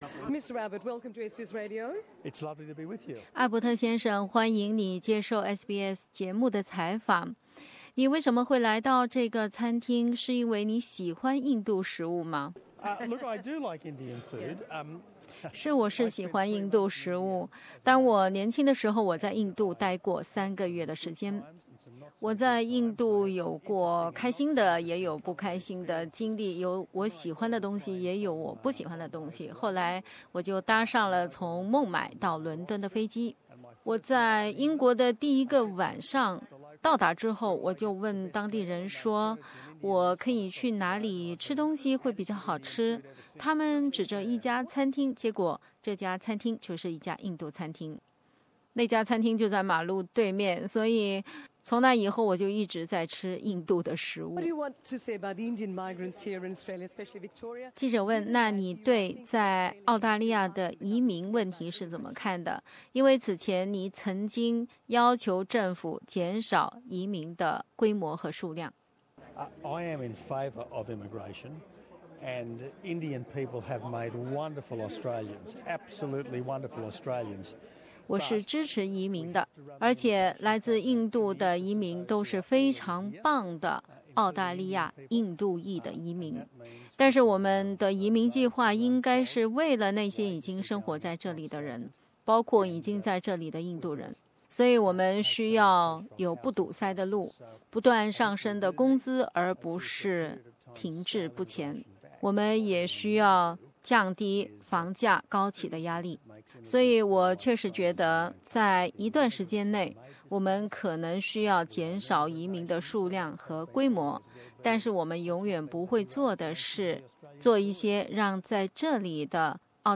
为此，SBS旁遮普语组记者在现场采访了艾伯特。 面对移民问题，他为自己的观点辩护；至于自己是否会再次成为澳大利亚总理，他则顾左右而言他，不肯定也不否定。